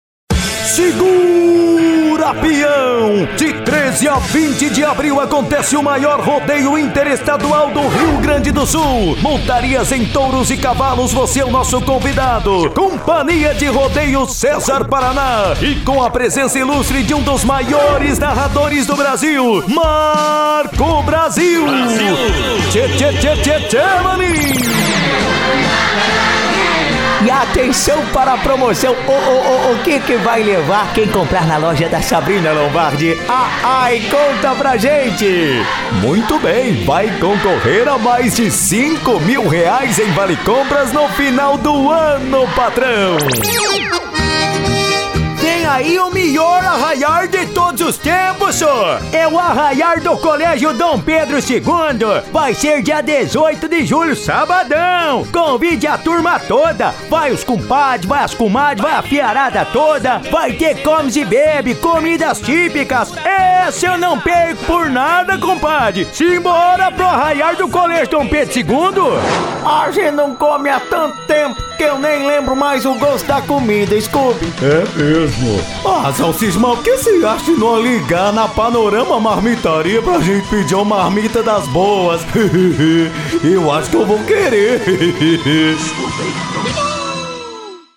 Demo de Vozes Caricatas :
Caricata